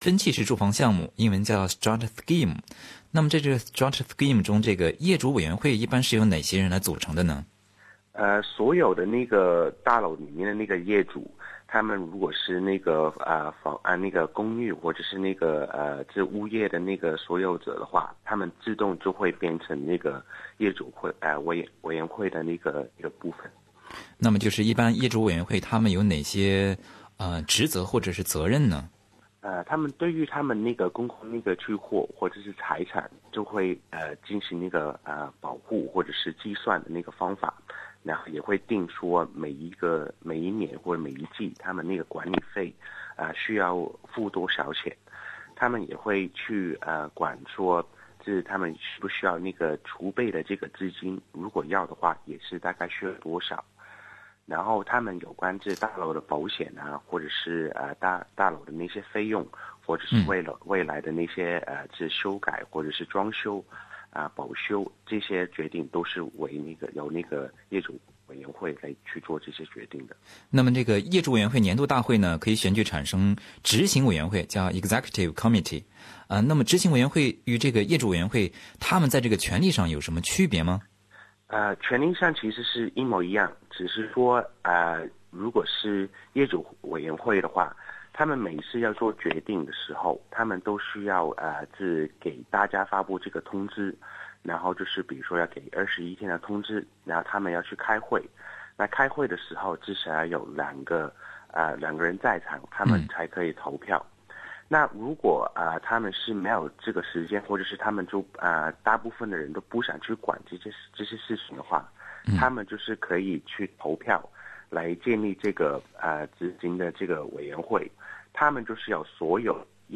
随着社会的发展，人口的增加，澳大利亚各大城市都加大了公寓的建设力度，越来越多的人选择住进了公寓，不论您是业主还是租客，多多少少总会和业主委员会（owners corporation）或者物业管理中介打交道，理清业主委员会的职责和权利很有必要。本期《现场说法》听众热线节目